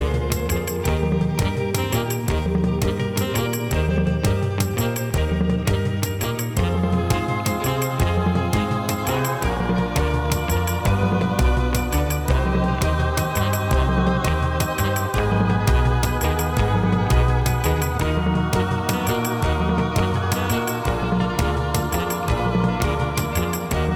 Minus Guitars Pop (1960s) 1:52 Buy £1.50